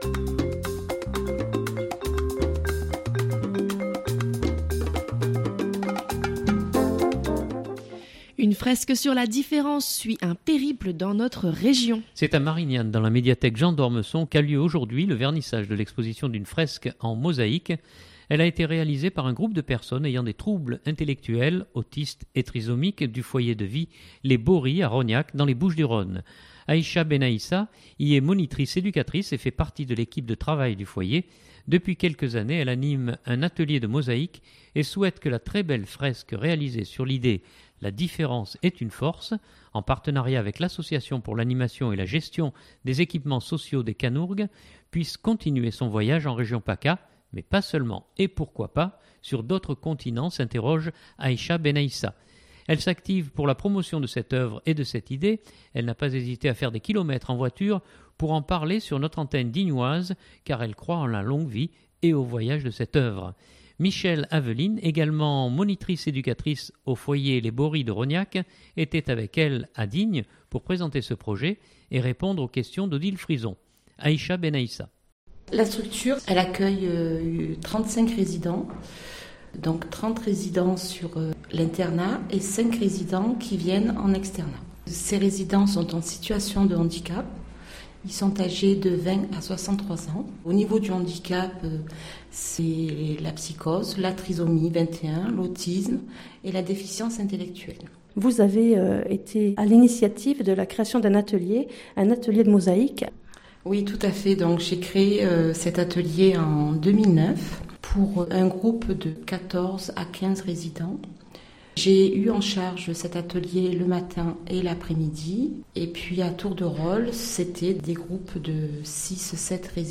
Elle n’a pas hésité à faire des kilomètres en voiture pour en parler sur notre antenne dignoise car elle croit en la longue vie, et au voyage de cette œuvre.